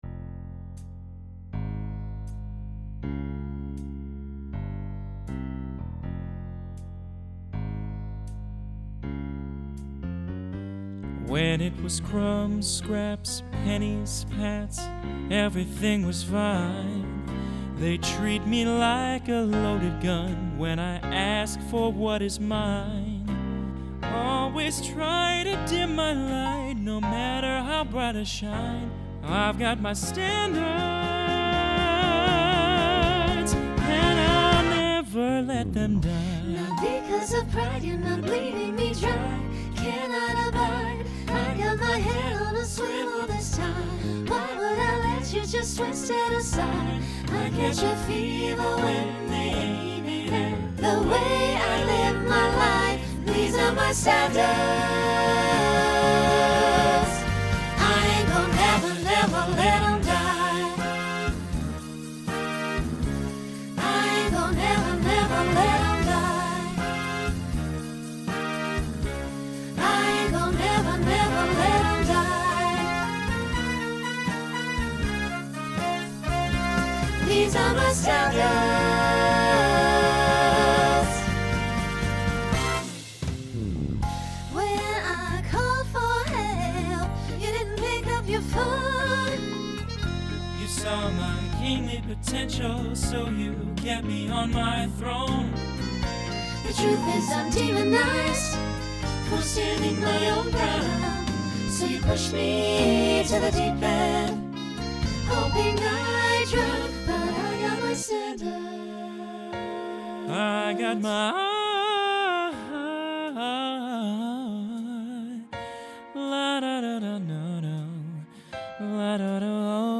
Swing/Jazz Instrumental combo
Solo Feature Voicing SATB